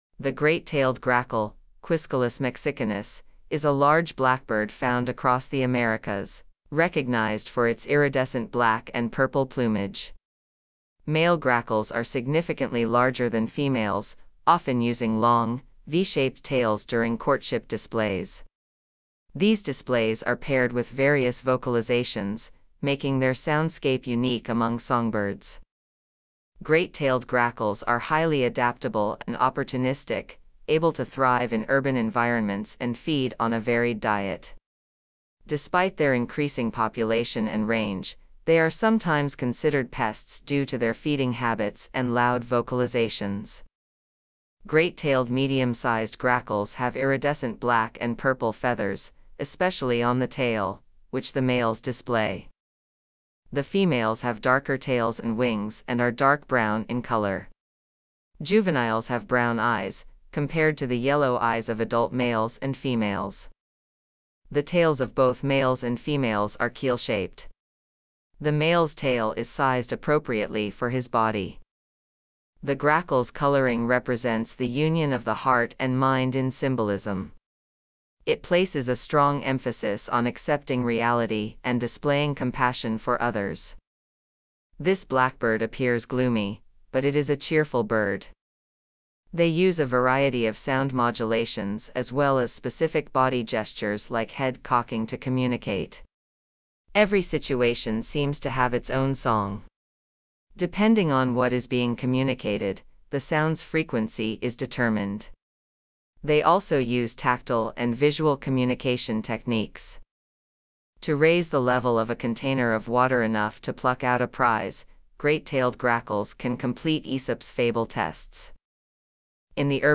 These displays are paired with various vocalizations, making their soundscape unique among songbirds.
Despite their increasing population and range, they are sometimes considered pests due to their feeding habits and loud vocalizations.
Great Tailed Grackle
Great-tailed-Grackle.mp3